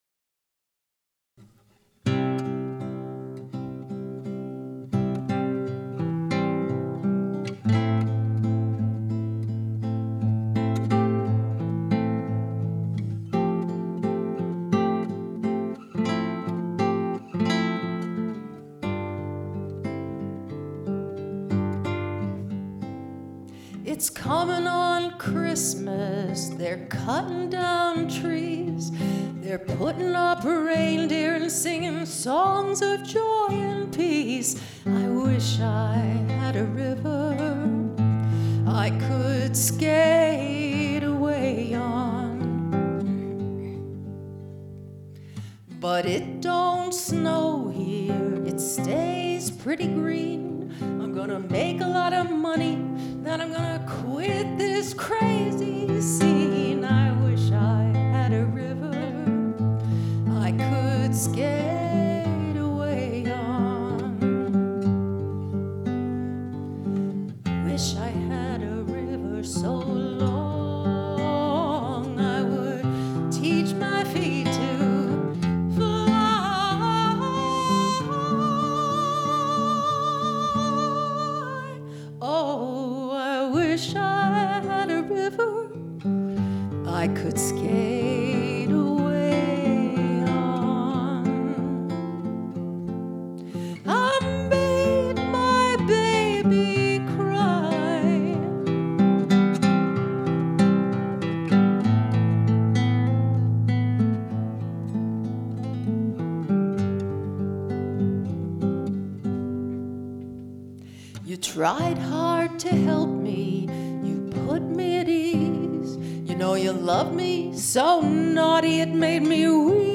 He invited me to do the singing while he did the playing.